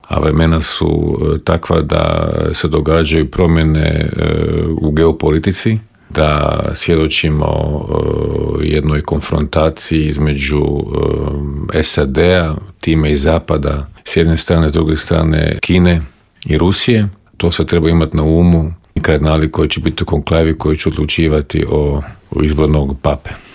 ZAGREB - Aktualne teme s naglaskom na početak konklave, izbor novog pape, ratne sukobe i situaciju u susjedstvu, prokomentirali smo u Intervjuu Media servisa s diplomatom i bivšim ministarom vanjskih poslova Mirom Kovačem.